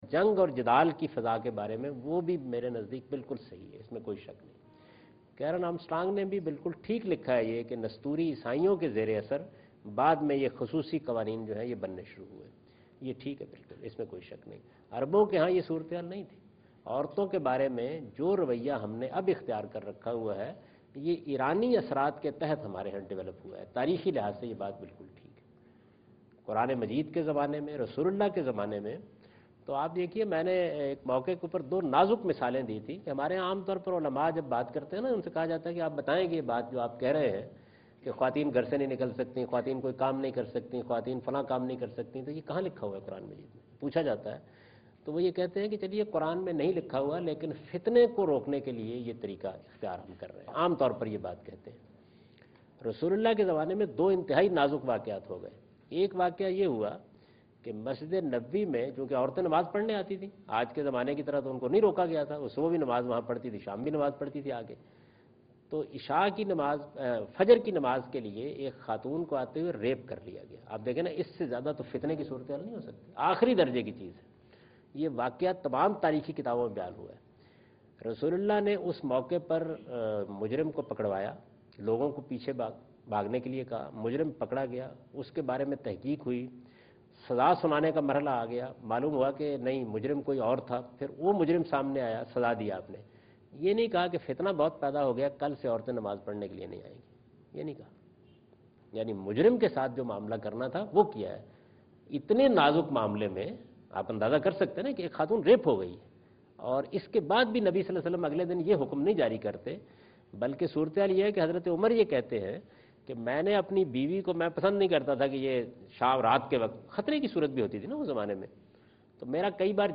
آج ٹی وی کے پروگرام آج اسلام میں جاوید احمد صاحب غامدی ”قران کیاہے؟ اسےکیسےسمجھیں؟“ سے متعلق سوالات کے جواب دے رہے ہیں